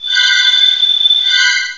sovereignx/sound/direct_sound_samples/cries/gothitelle.aif at master
gothitelle.aif